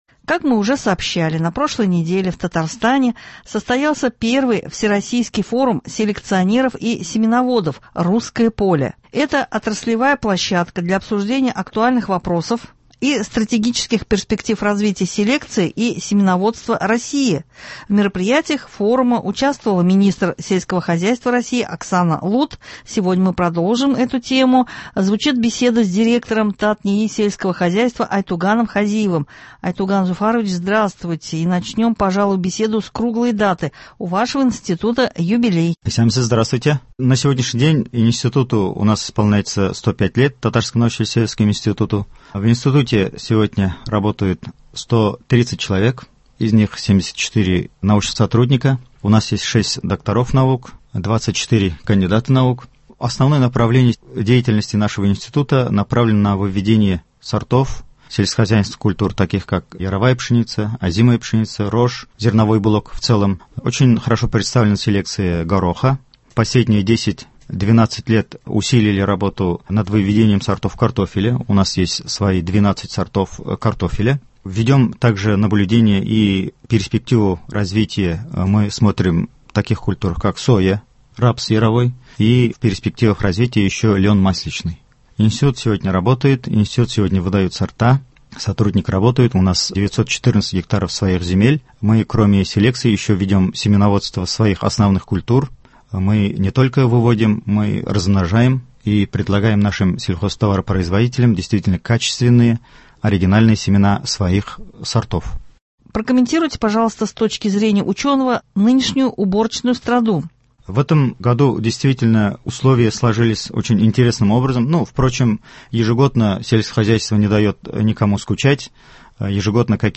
Сегодня мы продолжим тему – звучит беседа